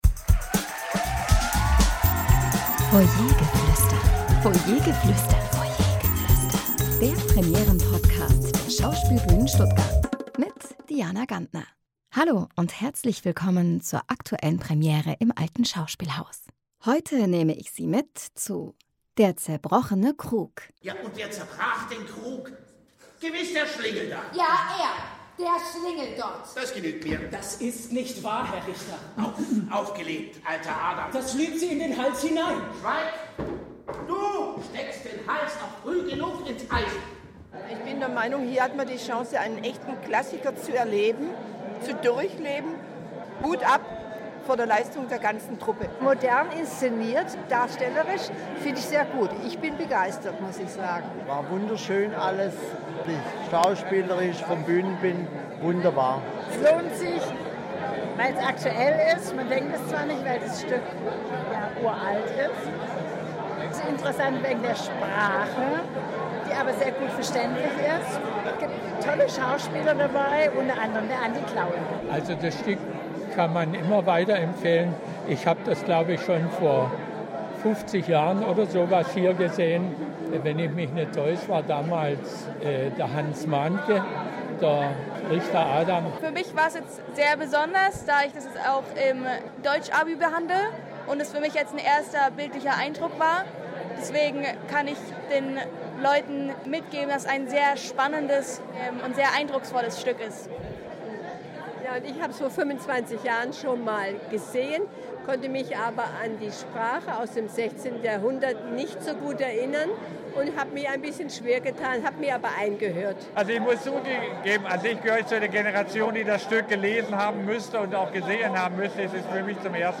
Publikumsstimmen zur Premiere von “Der zerbrochne Krug”